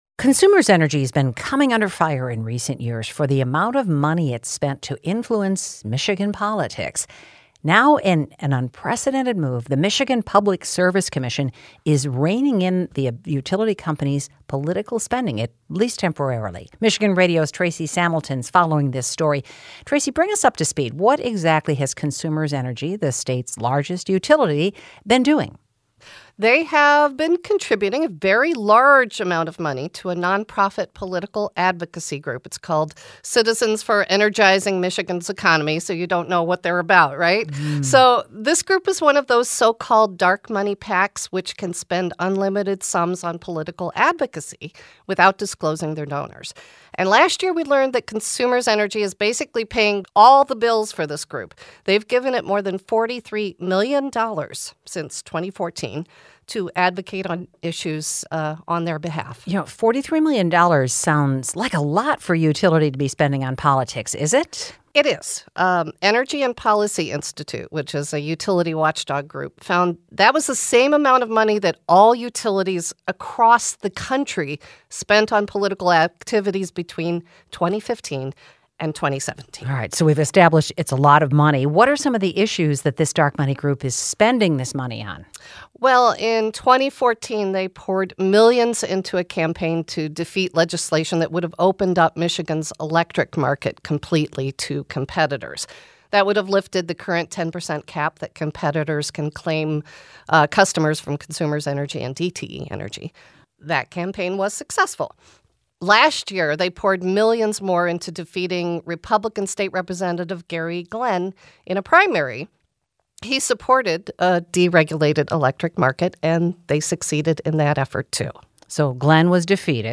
Stateside’s conversation